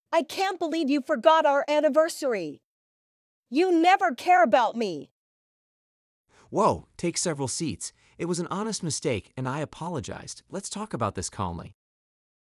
テイク セヴラル シーツ